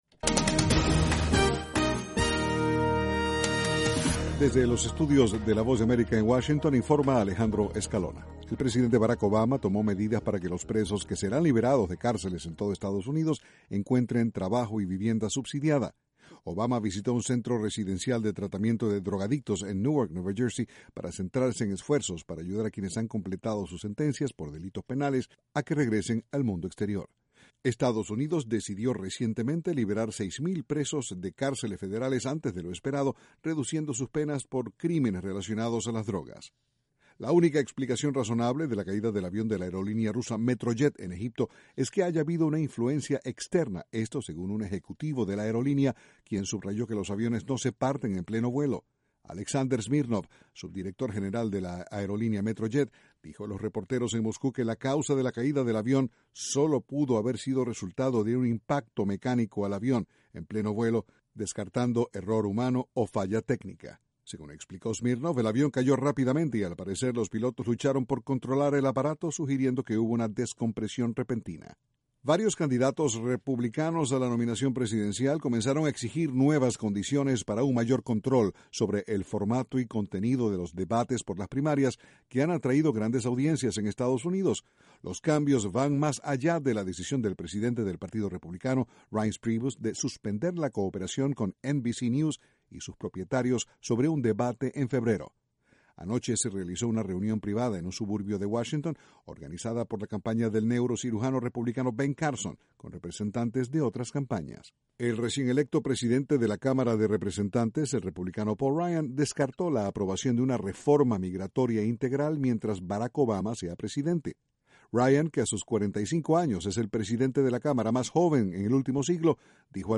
VOA: Noticias de la Voz de América, Washington.